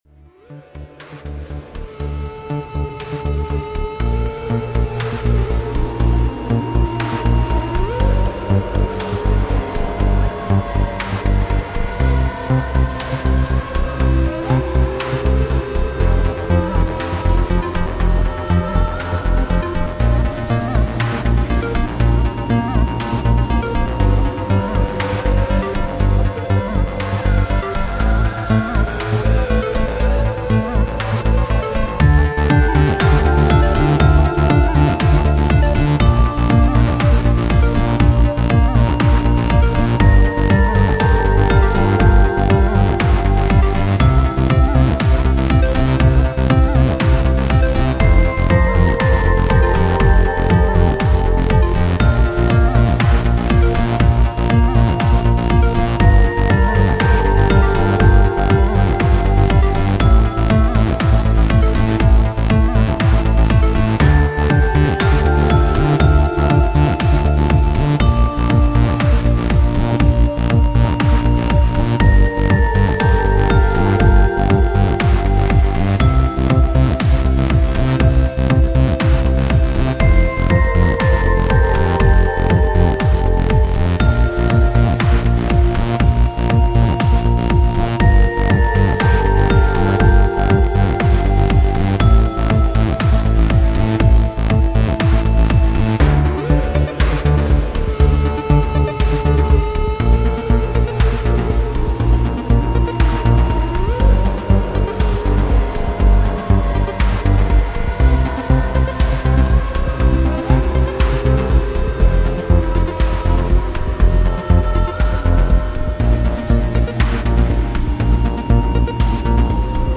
Электронная музыка New Age